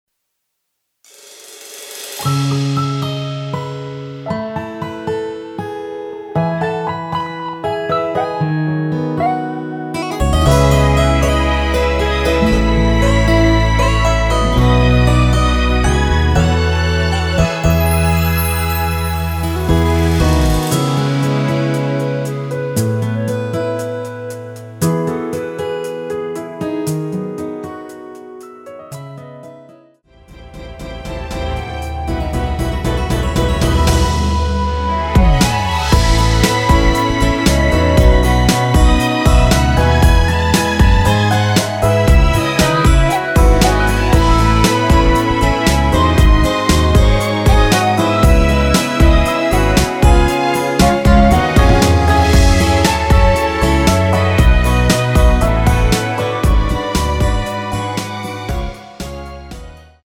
여성분이 부르실수 있는 키의 MR입니다.
원키에서(+4)올린 멜로디 포함된 MR입니다.(미리듣기 참조)
C#m
앞부분30초, 뒷부분30초씩 편집해서 올려 드리고 있습니다.